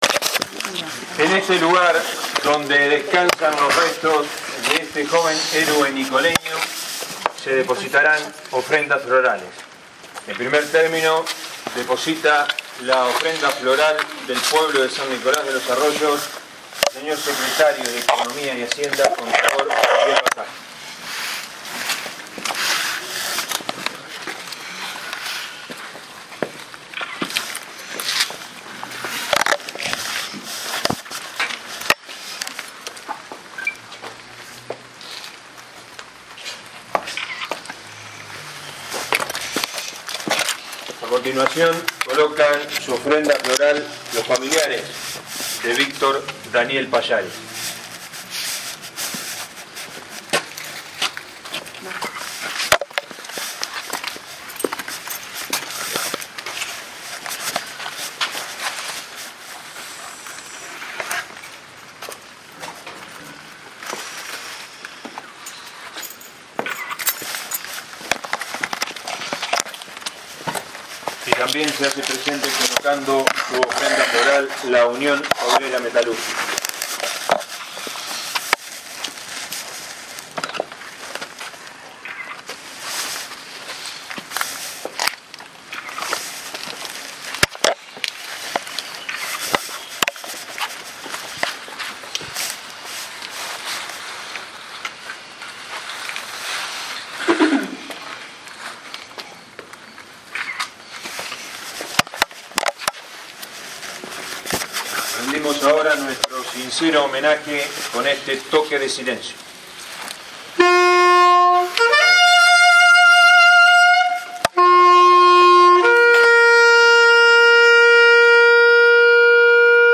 ENTREGA DE OFRENDAS FLORALES EN EL CEMENTERIO. ” 2 de Abril 1982 a 35 años de la Gesta de Malvinas
Como estaba previsto exactamente a la 9.30 hs. de este domingo, la lluvia no fue impedimento para dejar e realizar el emocionante acto.